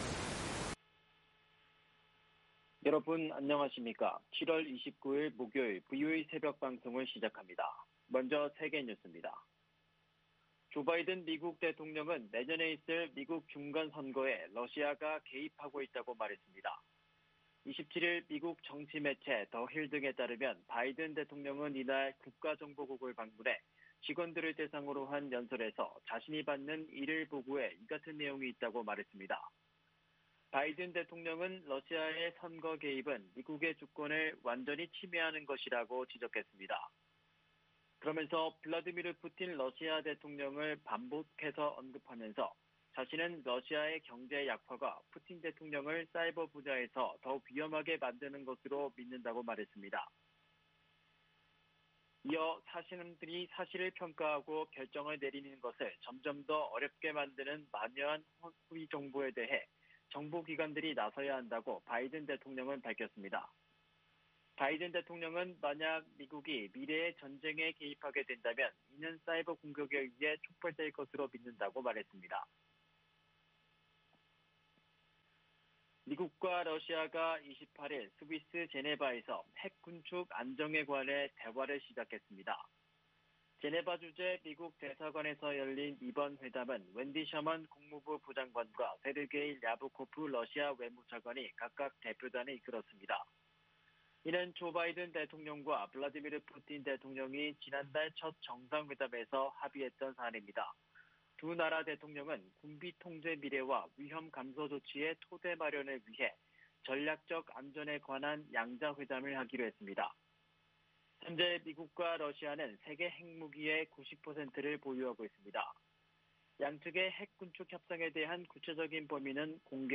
VOA 한국어 '출발 뉴스 쇼', 2021년 7월 29일 방송입니다. 미국 국무부는 남북한의 통신연락선 복원에 대해 지지와 환영의 뜻을 밝혔습니다. 미국 주요 언론들은 남북 통신연락선 복원이 남북간 긴장 완화에 도움이 될 것이라고 평가하고, 이번 조치의 주요 배경으로는 심각한 북한 경제난을 꼽았습니다. 미국은 한국에 대한 방위 공약에 집중하는 가운데 북한과의 외교에도 열려 있다고 미 국방장관이 강조했습니다.